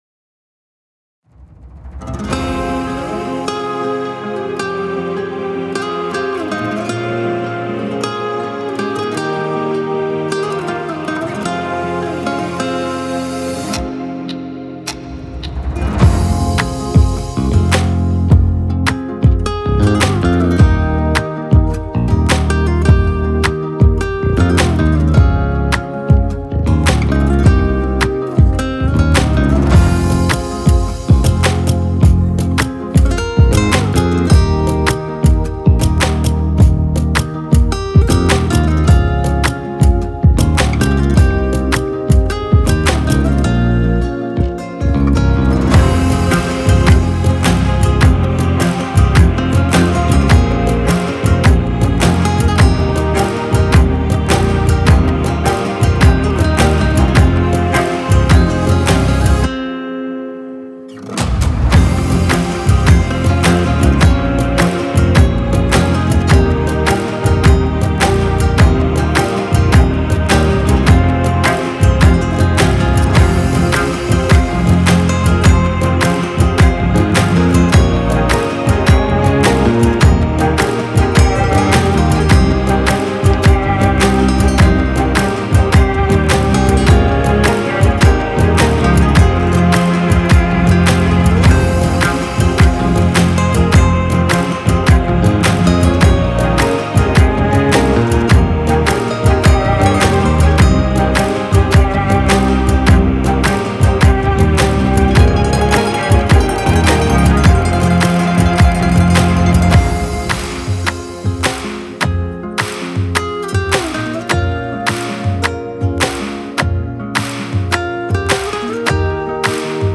پخش برخط آهنگ بیکلام و بدون آهنگ (آنلاین) play online
سرود